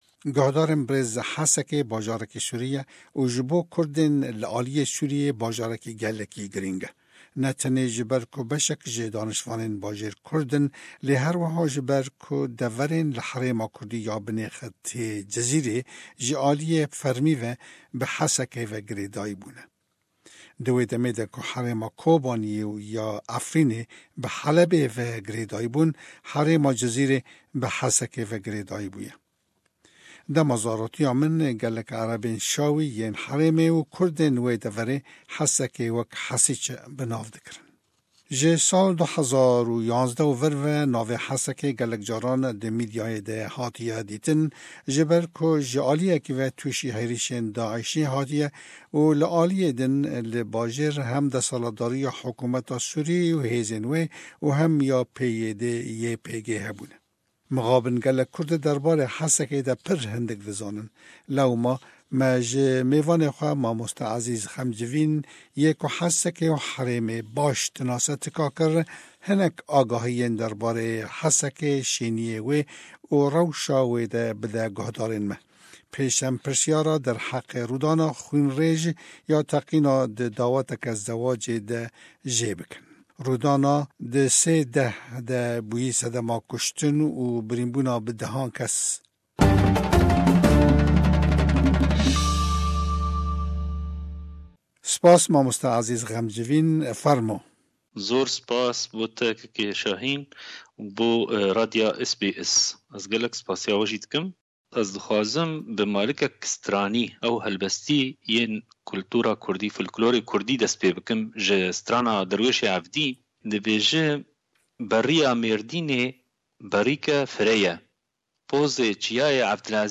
Hesekê: Hevpeyvîn